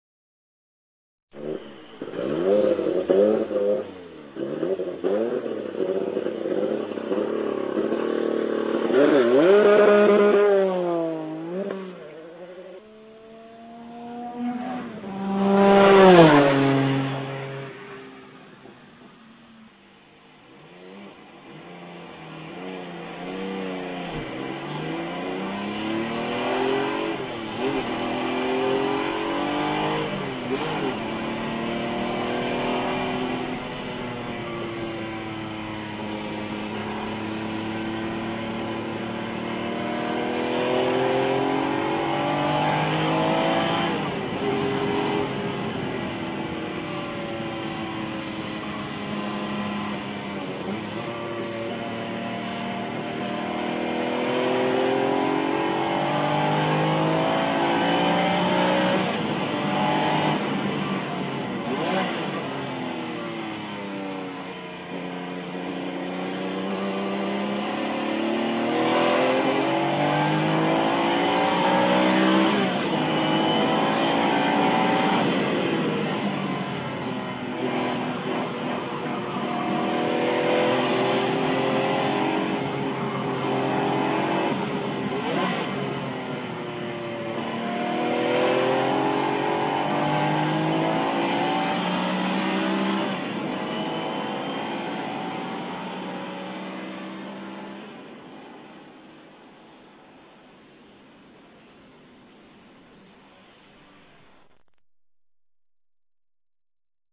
A jármű hangja általában hármas felosztásban hallható:
indulás, elhaladás, kocsiban ülve.
Porsche_962.rm